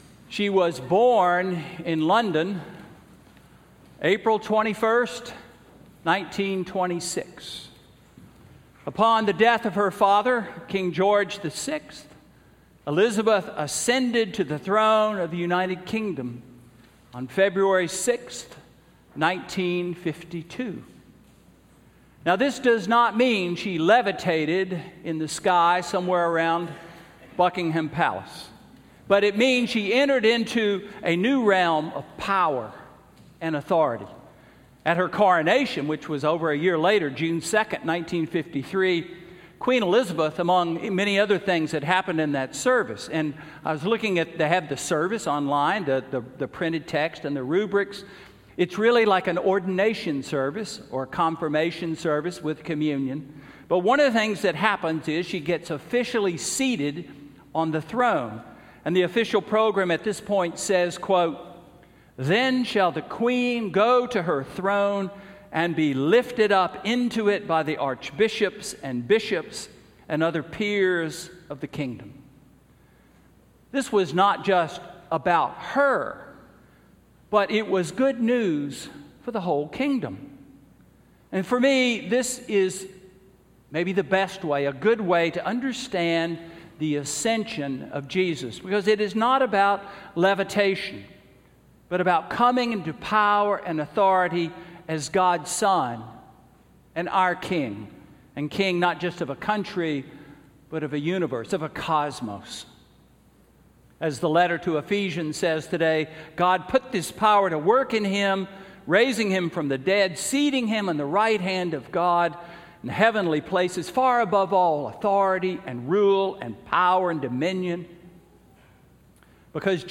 Sermon–Ascension–May 8, 2016